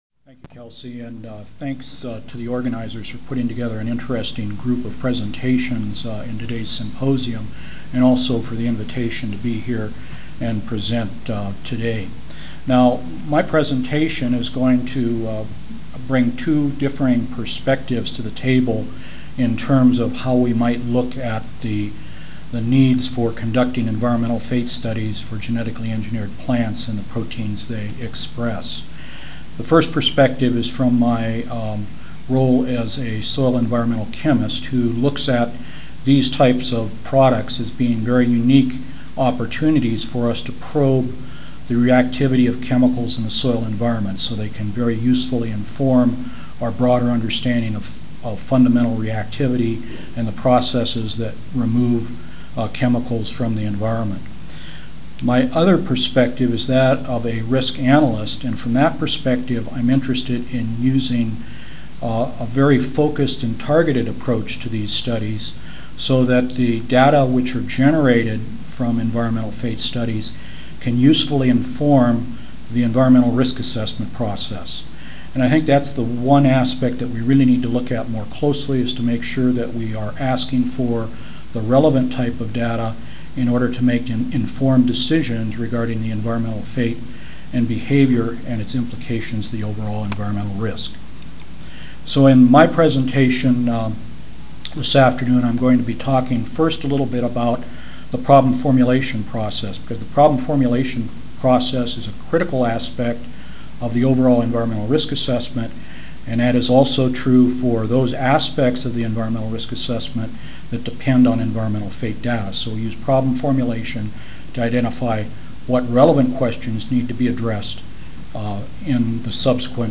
Room D6, First Floor (Reno-Sparks Convention Center)